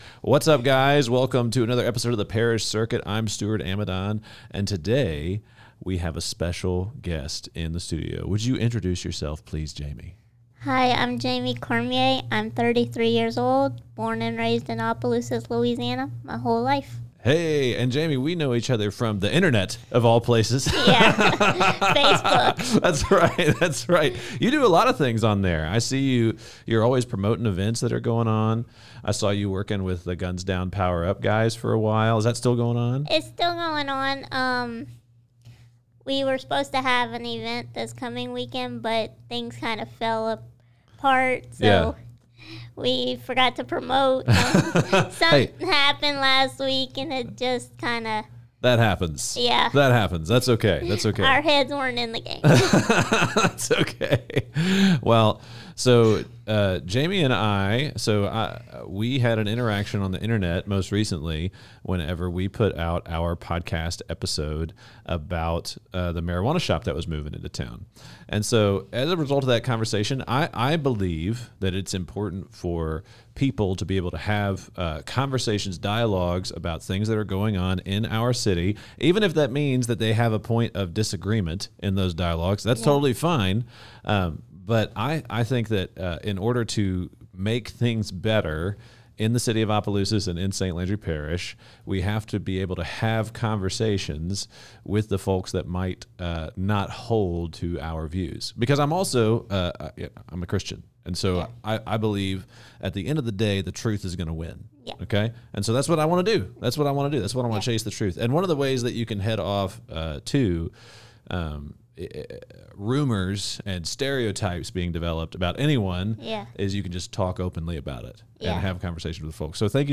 Holding interviews, stories, and news intended to inspire and cultivate the future of the South.